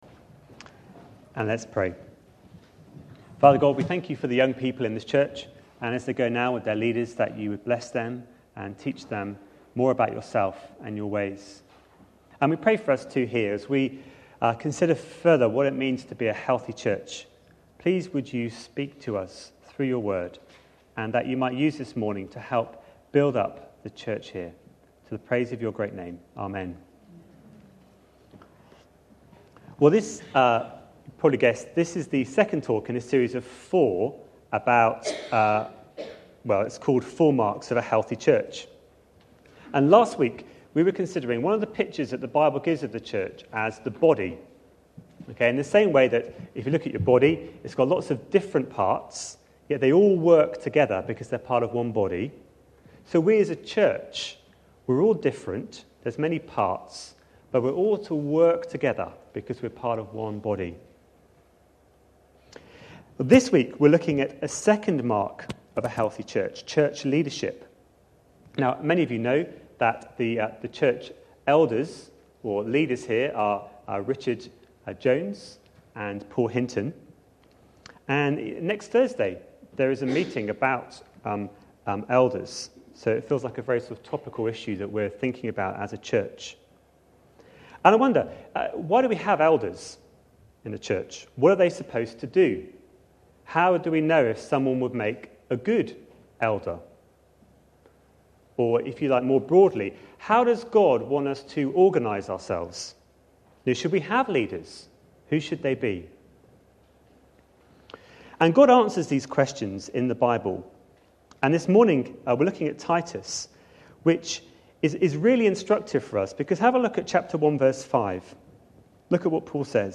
A sermon preached on 26th June, 2011.